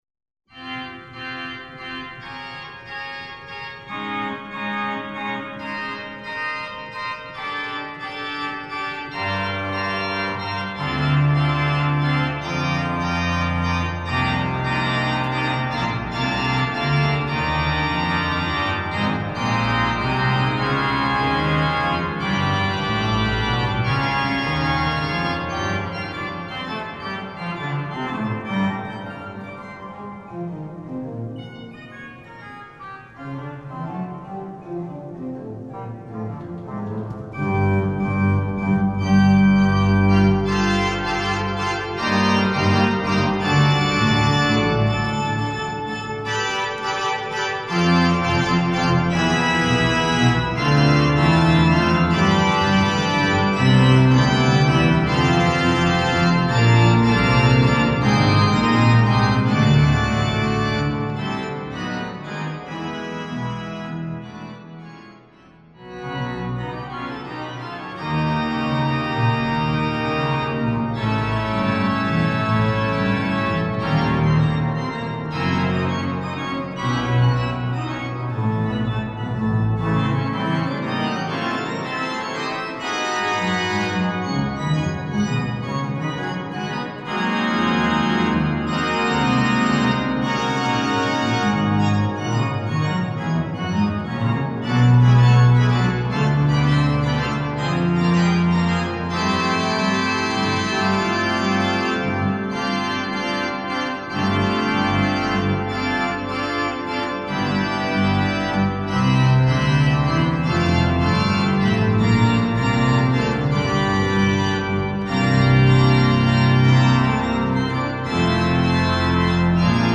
Voicing: Organ Solo